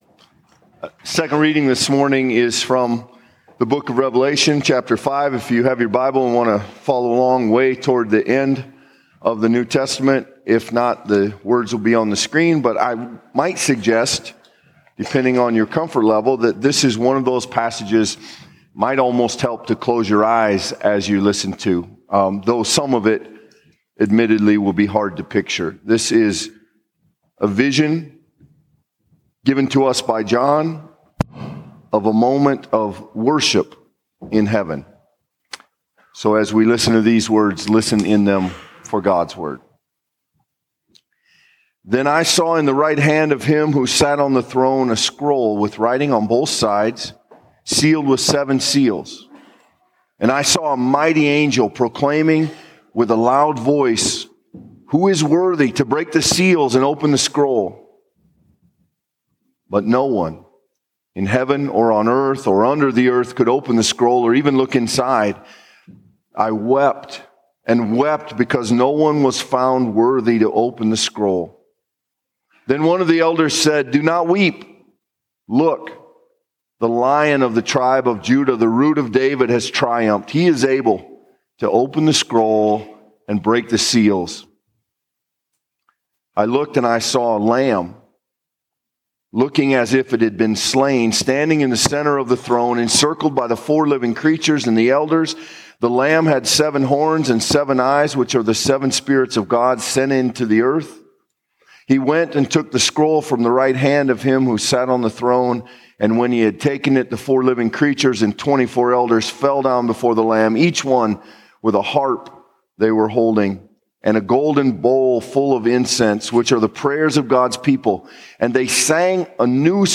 The 8:50 worship service at First Presbyterian Church in Spirit Lake.